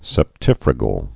(sĕp-tĭfrə-gəl)